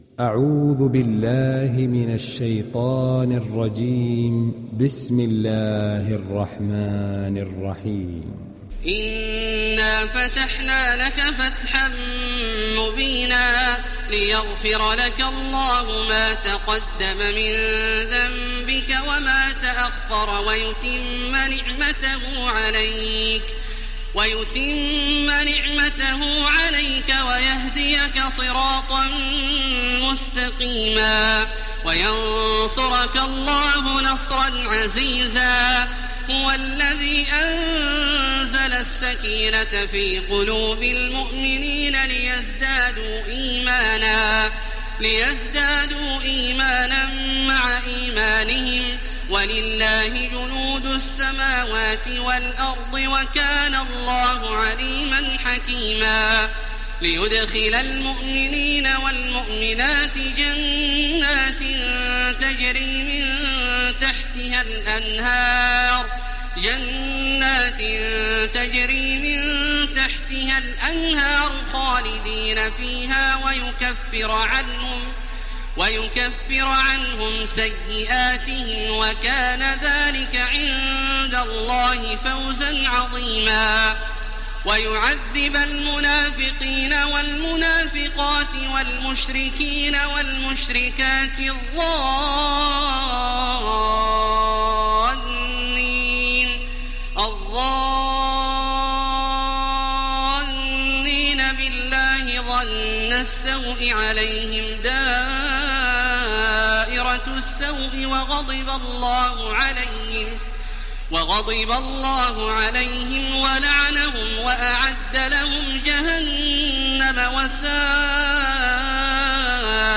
Download Surat Al Fath Taraweeh Makkah 1430